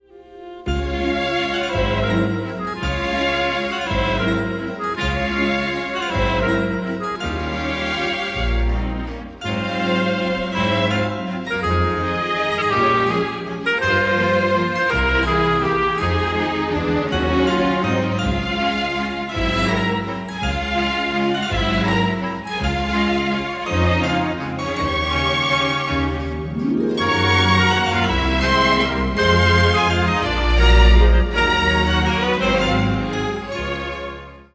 tense suspense music